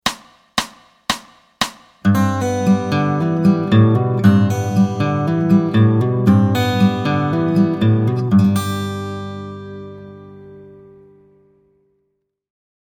As for the MP3 audio examples herein, I played them all fingerstyle, angling my fingers in a slight manner so that only my fingertip pads touched the strings, preserving my fingernails.
Open G Chord
By grabbing the “G” root (3rd fret, 6th string) of an open G chord with your fret-hand's ring finger (keeping it anchored throughout), your remaining digits become available to add all sorts of ornaments to this shape.
Fig. 2 below, the index finger can be used to pull-off from “C” (1st fret, 2nd string) to the open B string.
OpenChordsWithFingersG.mp3